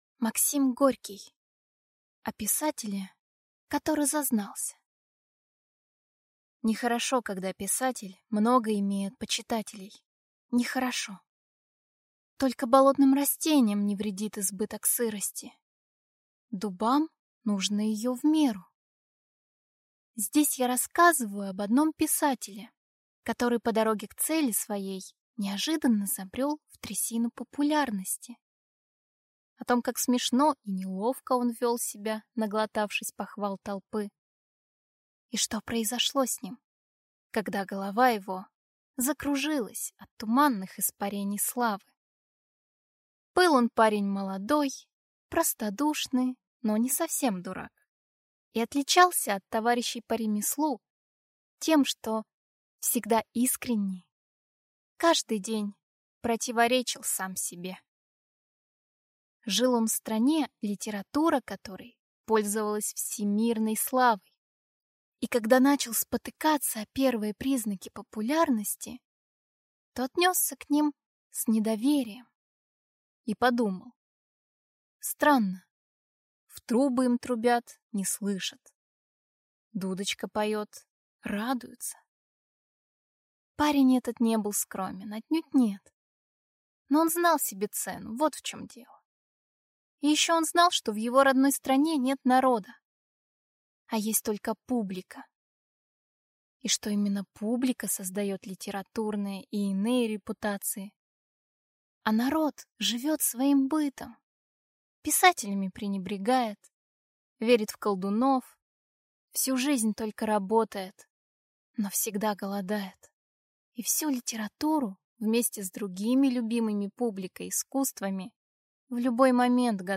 Аудиокнига О писателе, который зазнался | Библиотека аудиокниг